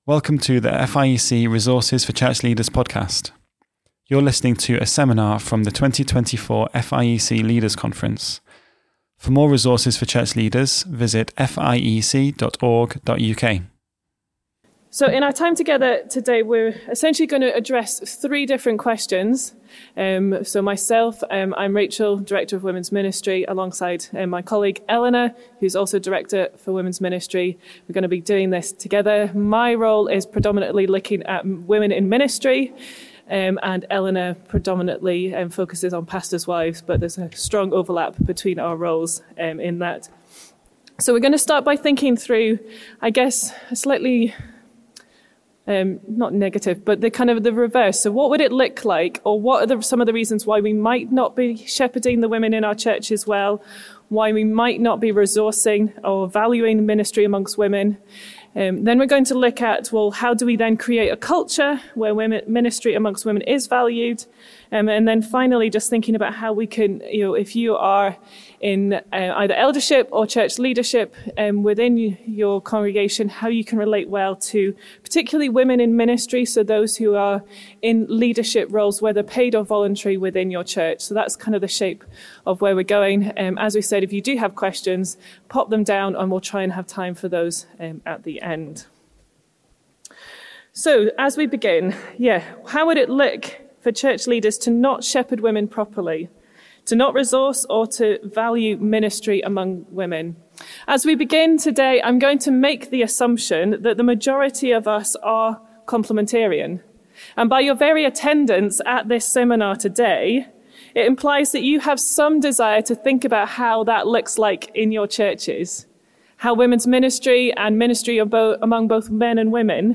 How do we create a culture where women’s ministry is resourced well and appropriately valued? A seminar for men from the 2024 Leaders' Conference.